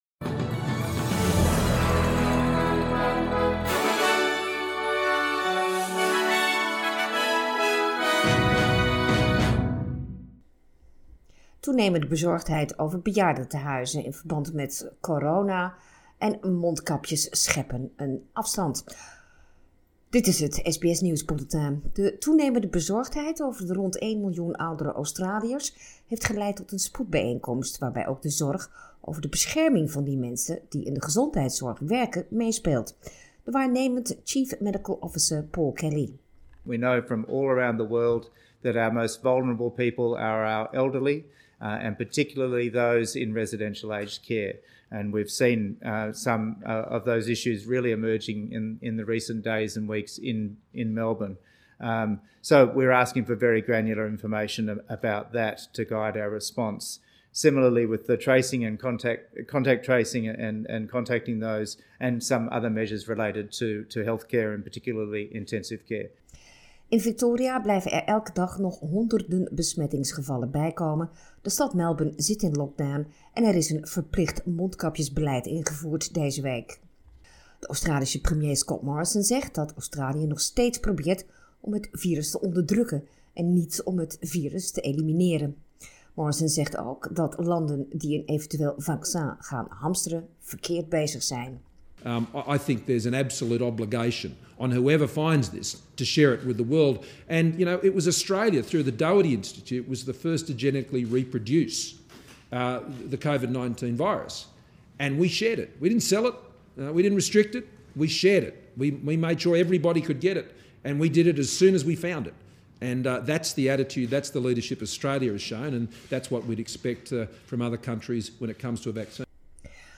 Nederlands/Australisch SBS Dutch nieuws bulletin zaterdag 25 juli
Het SBS Dutch nieuws bulletin van zaterdag 25 juli 2020